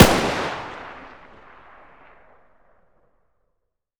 Index of /server/sound/weapons/g3a3
g3_distance_fire1.wav